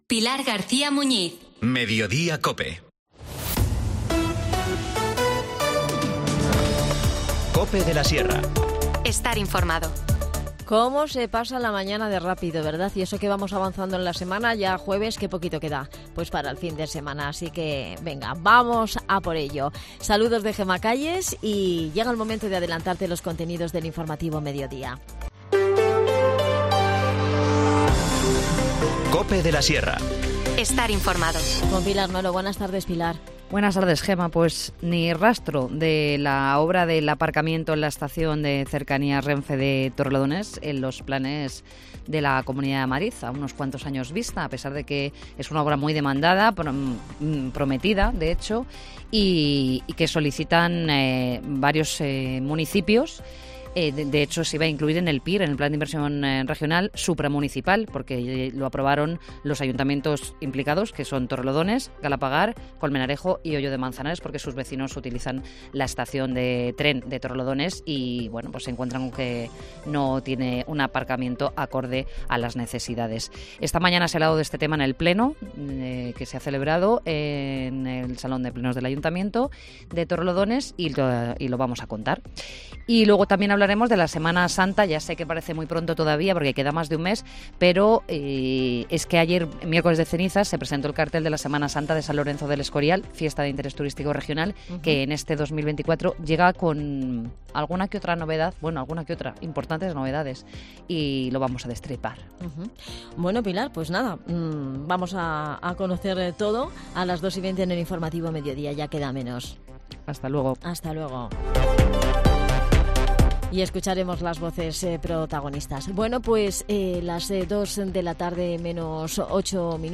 AUDIO: Charlamos con Adan Martínez, concejal de Comunicación en Collado Villalba, Capital de La Sierra, sobre toda actualidad del municipio que...